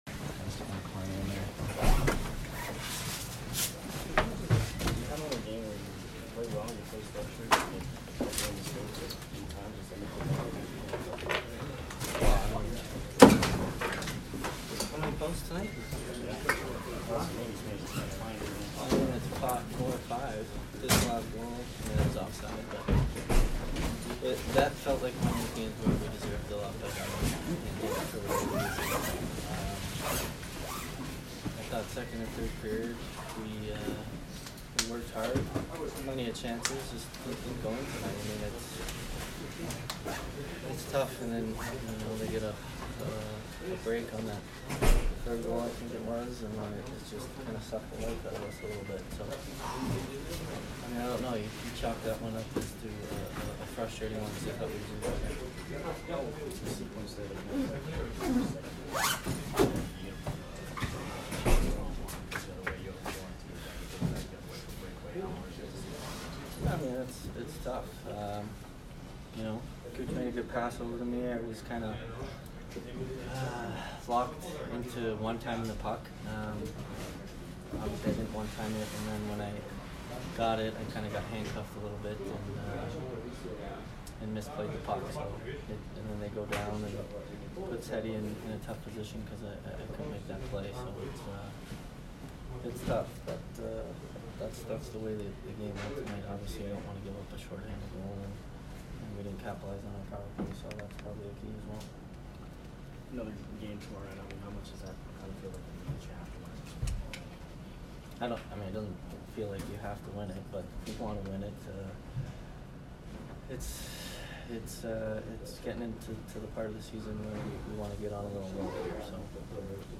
Stamkos post-game 12/9